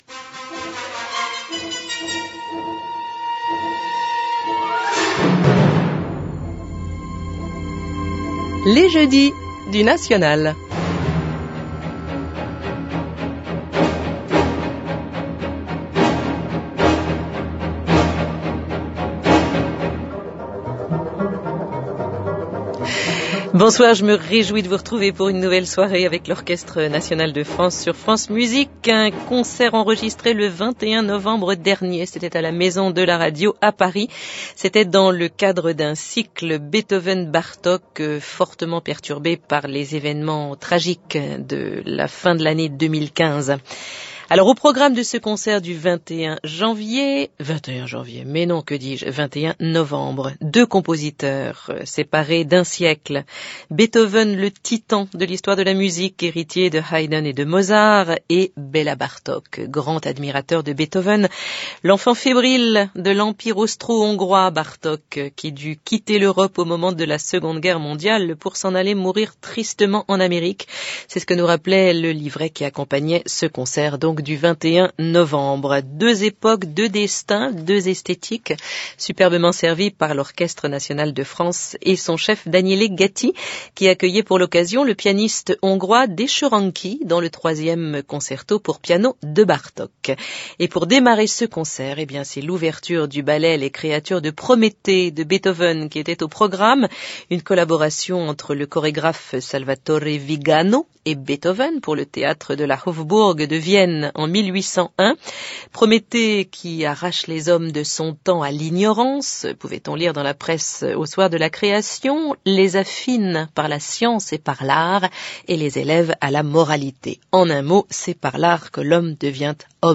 Dezsö Ranki with Daniele Gatti and Orchestre National de France In Concert – November 21, 2015 – Radio France Musique –
Dezsö Ranki, accompanied by the legendary Daniele Gatti and Orchestre National de France in a program of music by Beethoven and Bartok, and recorded last November 21st by the venerable Radio France Musique.
Ranki plays a perennial favorite, the 3rd Piano concerto by Bela Bartok. The Orchestra begins and ends the concert with Beethoven. Starting off with Creatures Of Prometheus and ending with Beethoven’s Symphony Number 7.